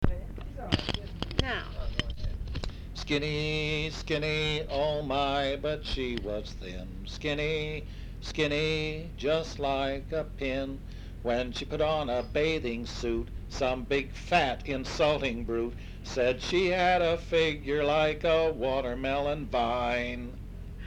Folk songs, English--Vermont
sound tape reel (analog)
West Brattleboro, Vermont